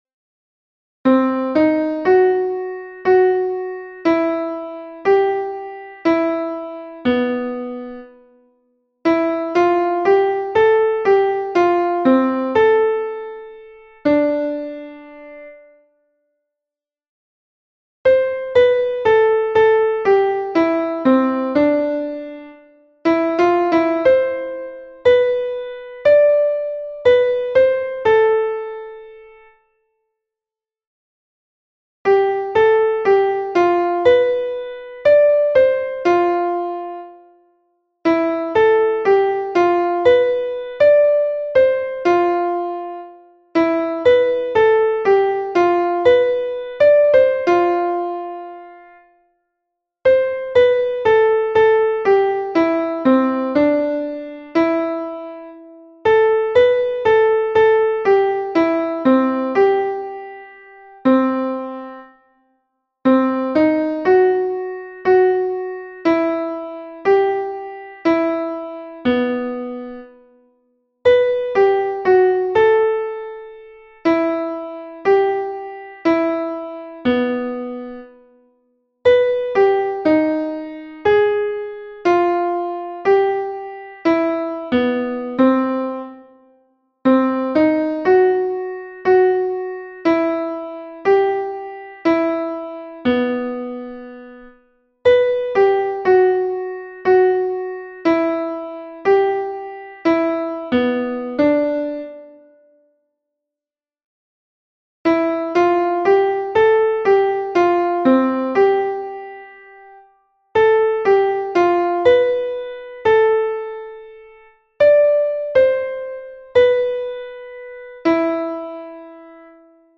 • Жанр: Военная